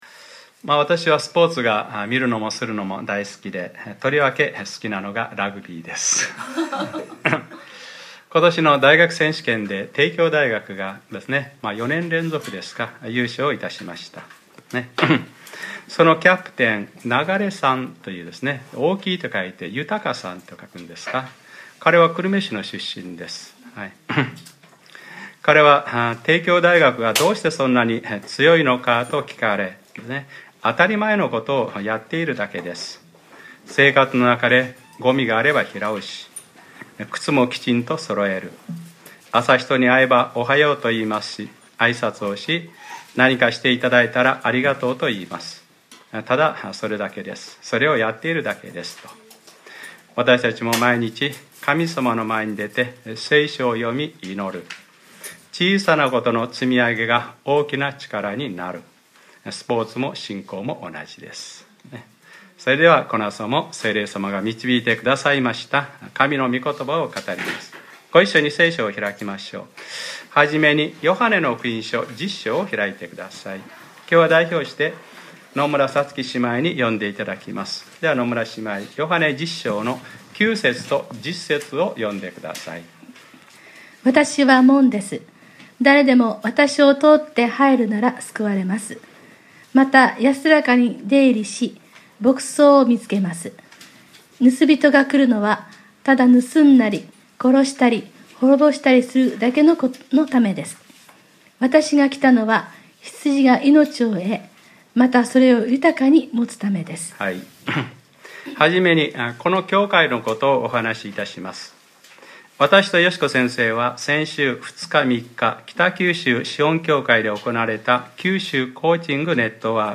2015年02月08日）礼拝説教 『聖徒たちを整えて奉仕の働きをさせ』